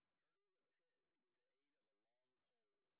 sp09_street_snr30.wav